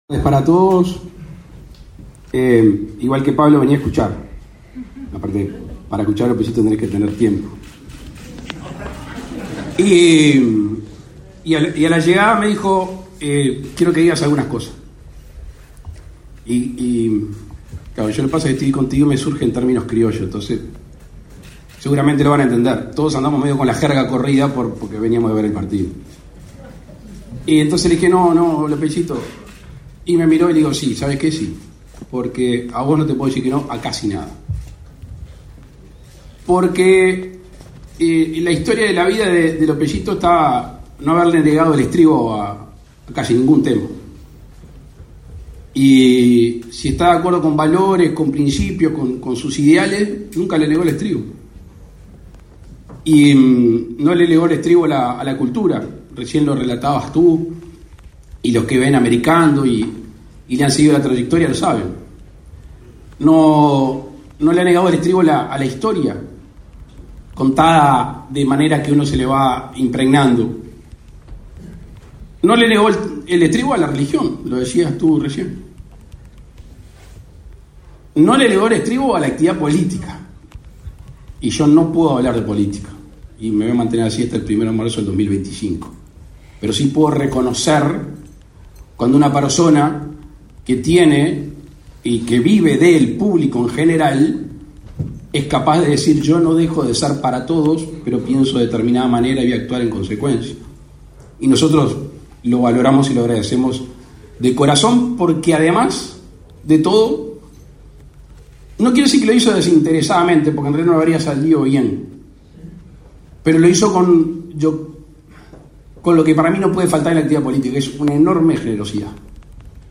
Palabras del presidente de la República, Luis Lacalle Pou
Este 28 de noviembre se realizó el acto por el 50.° aniversario del programa televisivo Americando con la participación del presidente de la República